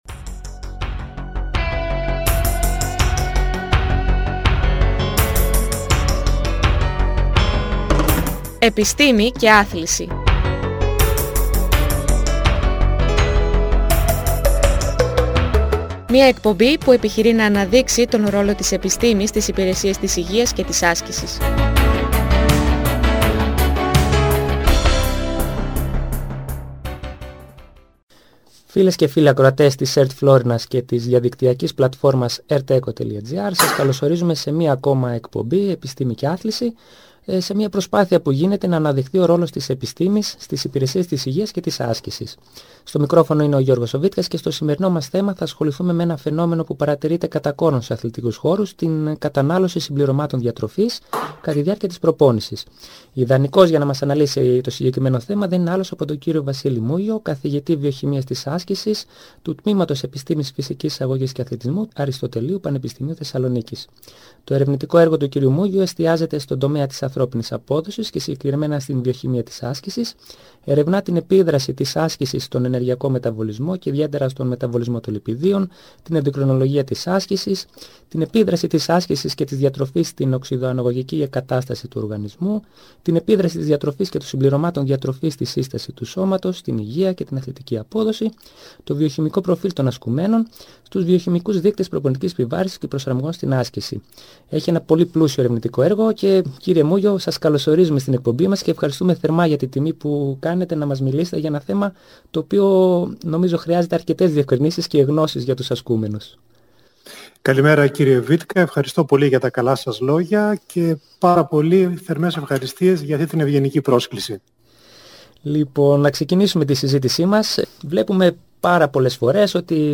Μια εκπαιδευτική εκπομπή όπου διακεκριμένοι καθηγητές και επιστήμονες, αναδεικνύουν τον ρόλο της επιστήμης στις υπηρεσίες της υγείας και της άσκησης. Σκοπός είναι να διευκρινιστούν και να απλοποιηθούν διάφορες έννοιες γνωστές και άγνωστες, που μπορούν να ωφελήσουν όλους όσους ασκούνται, να παροτρύνουν όσους απέχουν, να ξεκινήσουν την άσκηση και να δώσουν πολύτιμες πληροφορίες και συμβουλές σε όσους καθοδηγούν ασκούμενους, όπως είναι οι προπονητές, οι γυμναστές και άλλοι φορείς της άσκησης.